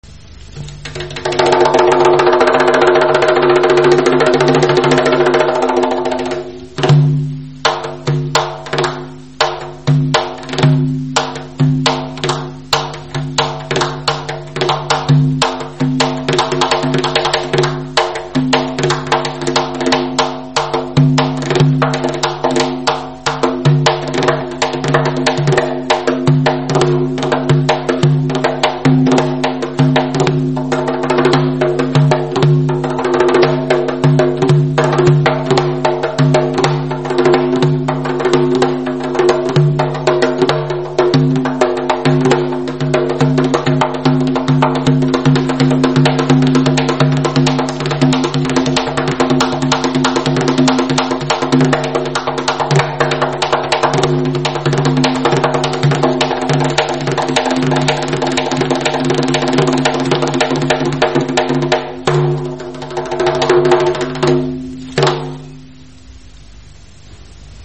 Qaval
Qaval - birüzlü zərb alətləri qrupuna aiddir.
Qoz ağacından hazırlanmış sağanağın içəri hissəsinə çevrəsi boyu 60-70 ədəd xırda mis halqalar bərkidilir.